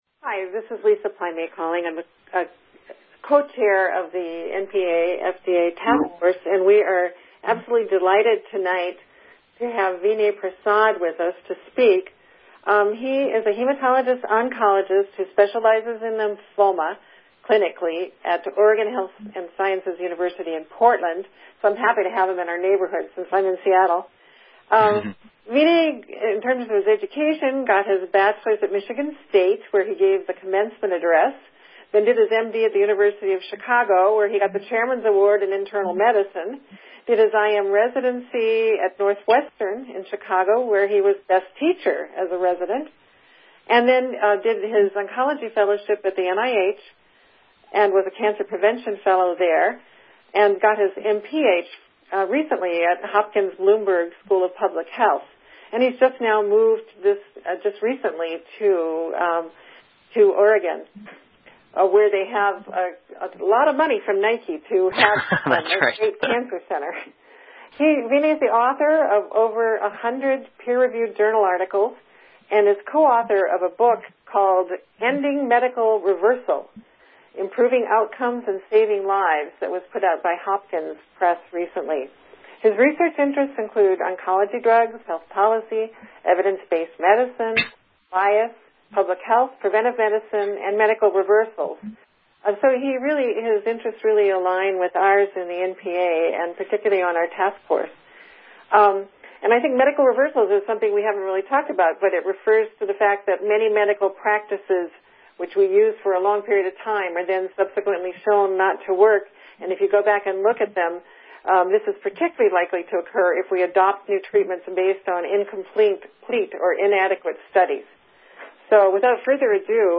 NPA FDA Task Force Webinar – Jan 2016